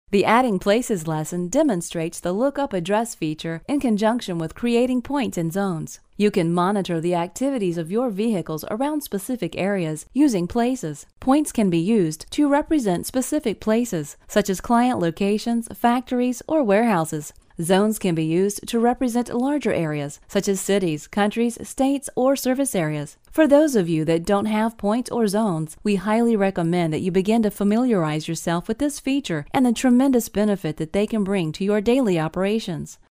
American female voice over...warm, friendly, business
mid-atlantic
Sprechprobe: eLearning (Muttersprache):
Professional female voice over for muliple projects.